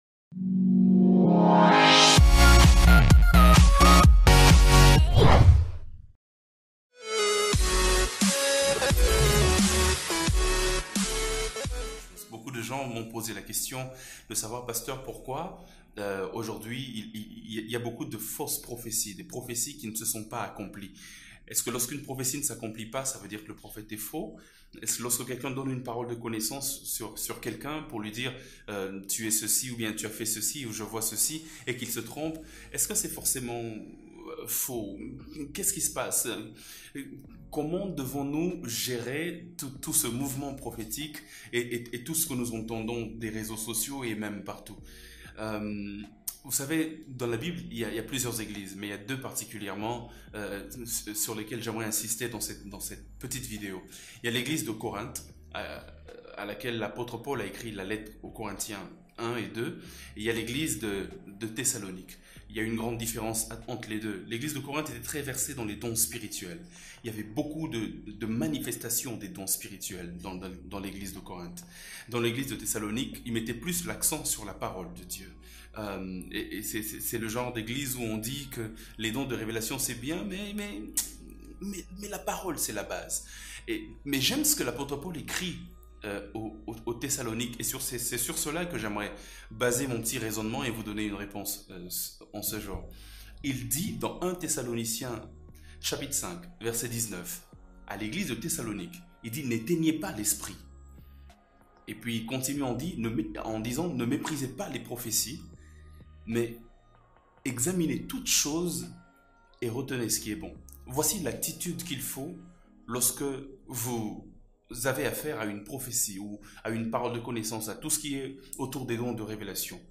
PRÉDICATIONS AUDIO | SCHOOL FOR CHRIST FONDATION